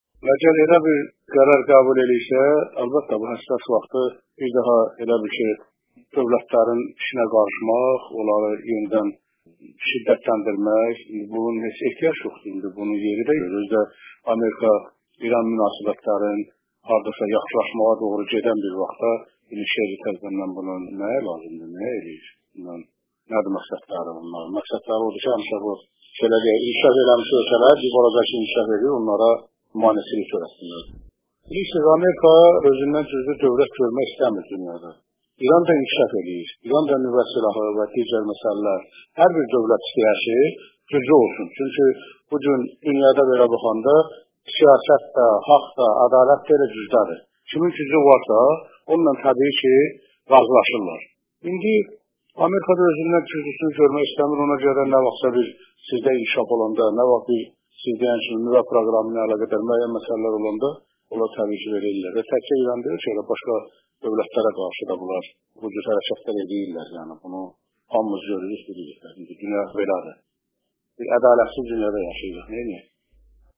ABŞ xarici işlər nazirinin BMT-də İrana qarşı yeni qətnamə qəbul etdirməyə can atması şübhəsiz ki inkişaf etmiş ölkələrə meneçilik törətməkdən başqa ola bilməz. Mözu ilə bağlı Az. R. deputatlarından olan İldar İbrahimoğlu Beynəlxalq Səhər Tele-kanalının Azəri Radiosu ilə fikirlərini bölüşərək deyib: Amerika İran münasibətlərinin yaxınlaşmaq doğru gedən bir vaxtda ABŞ xarici işlər nazirinin İran haqda belə bir hərəkətlərə əl atması yersizdir...